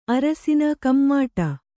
♪ arasina kammāṭa